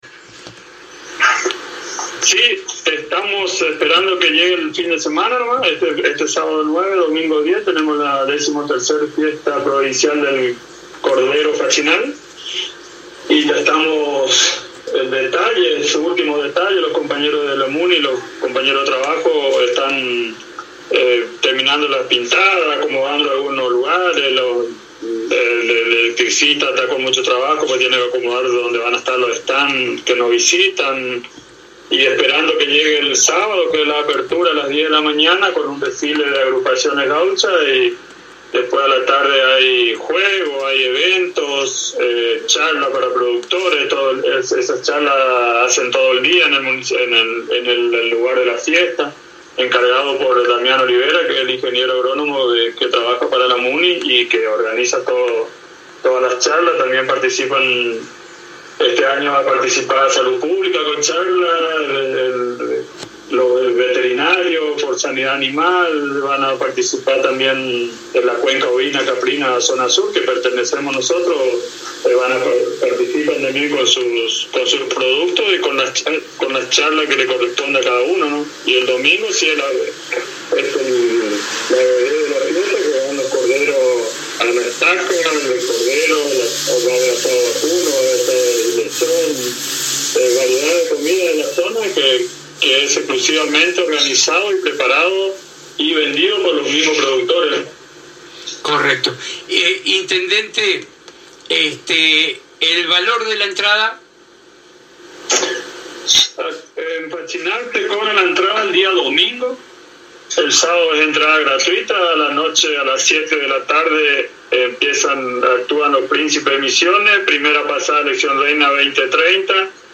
En dialogo exclusivo con la ANG a través de Éxito Fm, el Intendente de Fachinal Miguel Benítez, contó que esperan con ansias el fin de semana para la celebración de esta fiesta.